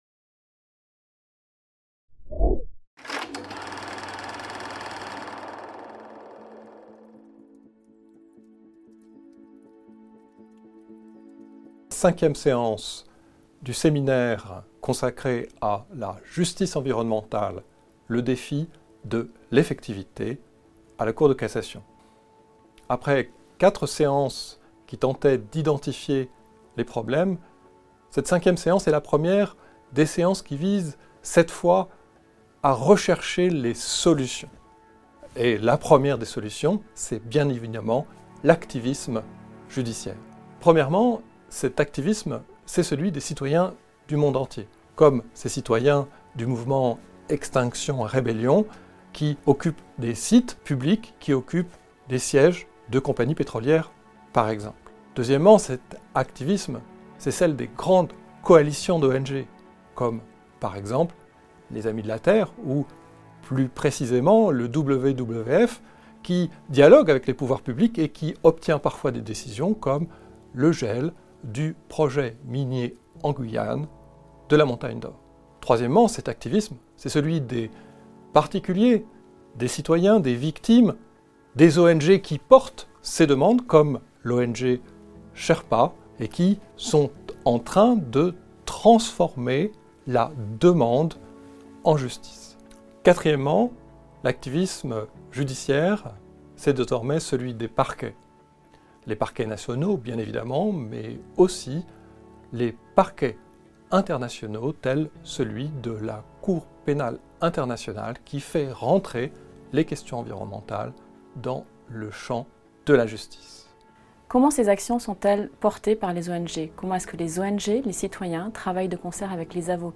Programme 2020/2021 du cycle bi-annuel de conférences à la Cour de cassation